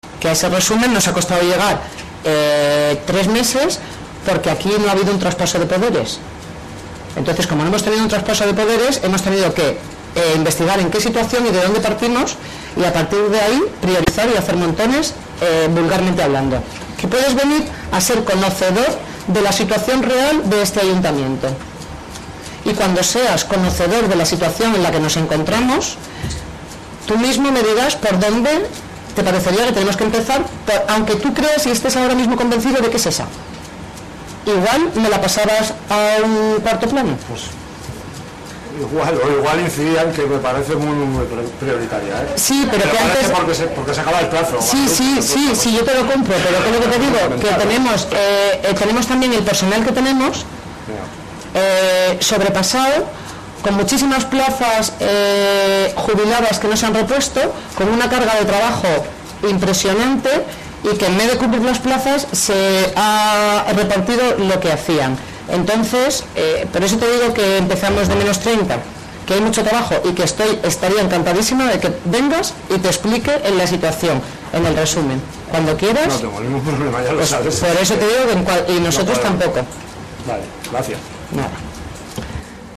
Una situación que el equipo de gobierno ha conocido en estos primeros meses, «porque no hubo traspaso de poderes» por parte del anterior tripartito, destacaba la alcaldesa Guadalupe Fernández.